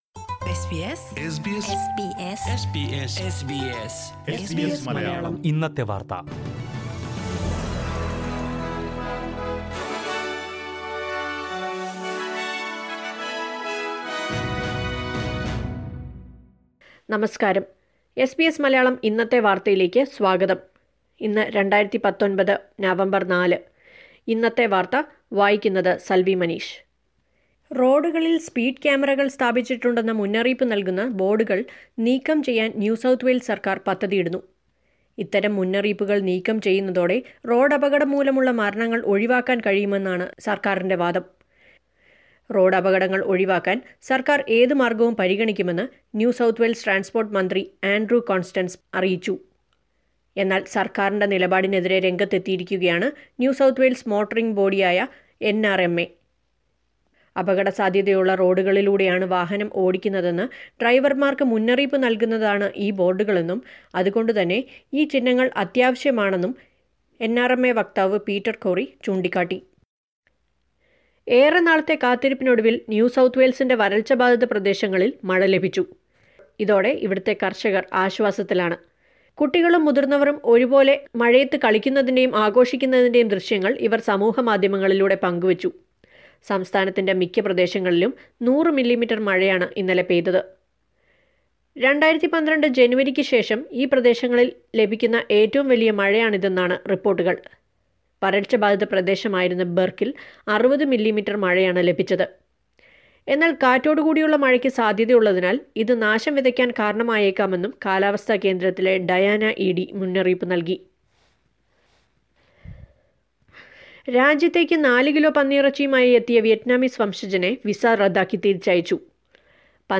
SBS Malayalam Today's News: November 04, 2019